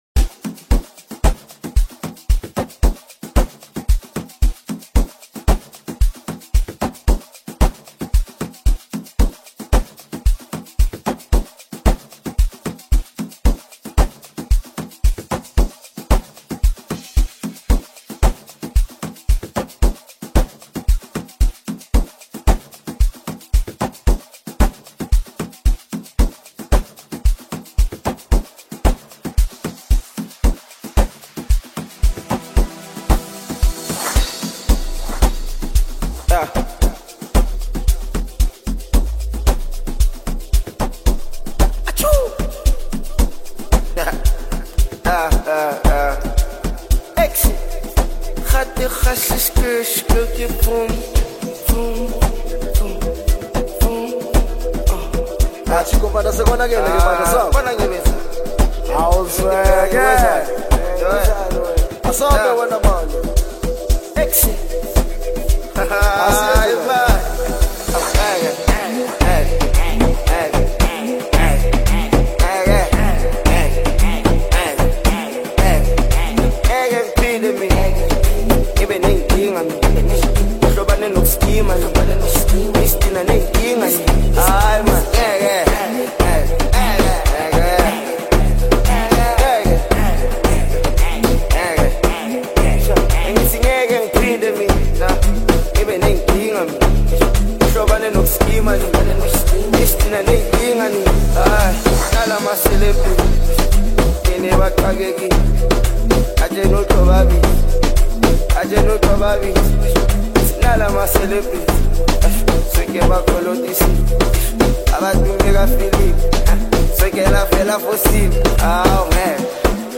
The instrumental arrangement is polished and well-balanced